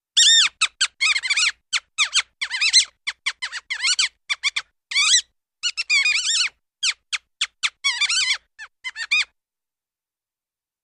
Busy, Medium-high Pitched Mouse Squeaks.